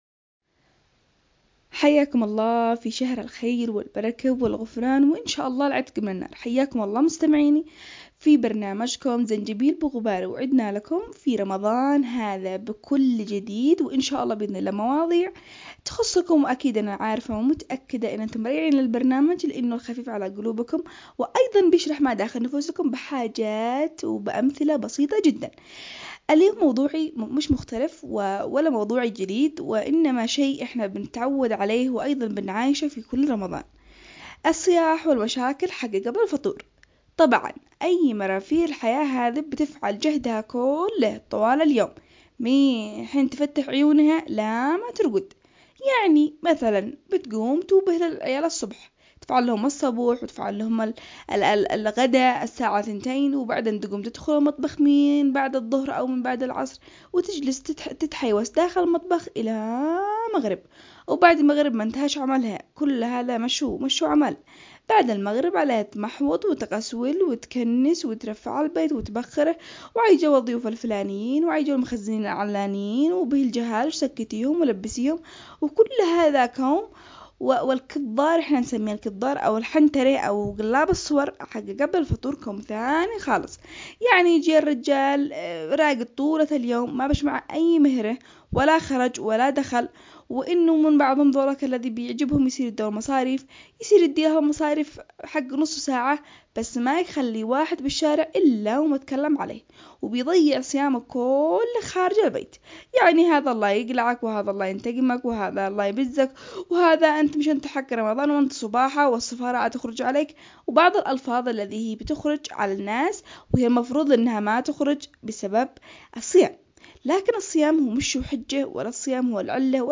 برنامج زنجبيل بغباره برنامج أسبوعي اجتماعي يناقش القضايا الخاصة بالنساء بأسلوب كوميدي و باللهجة العامية الصنعانية